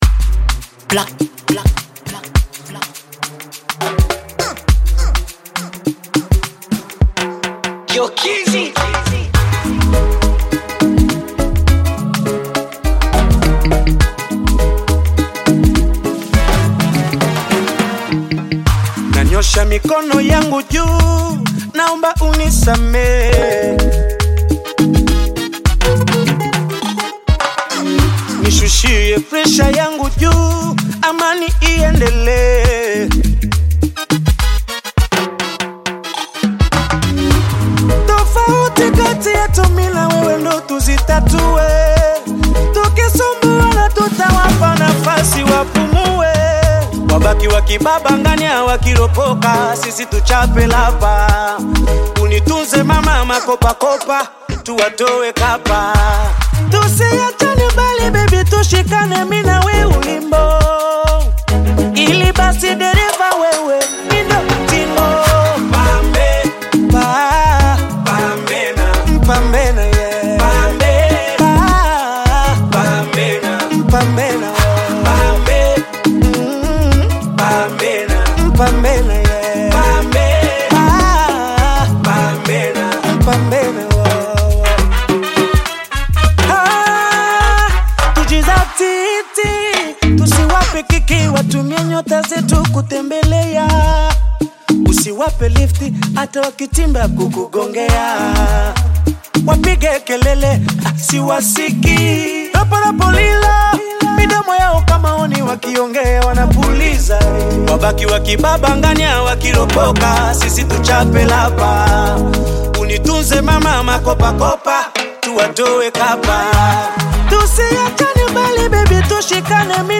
is a lively Afro-pop single